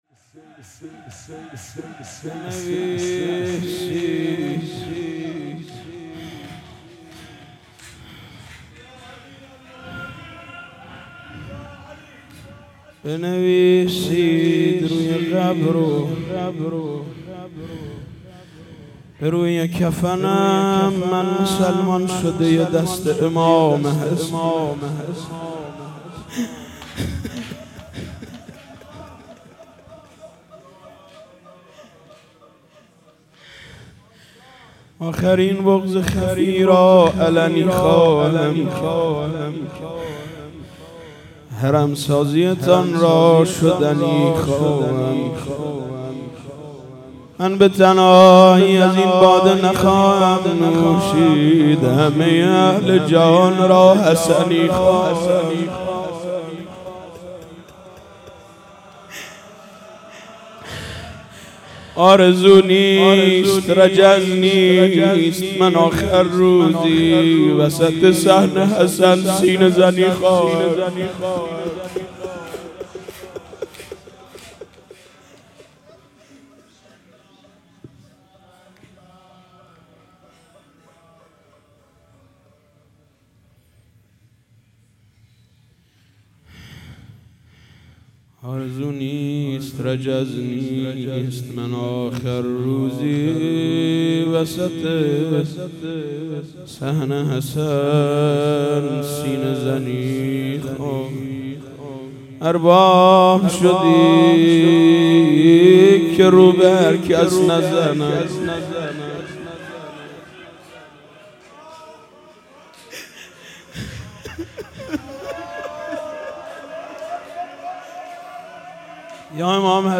شهادت حضرت امام حسن مجتبی(ع)/16آبان97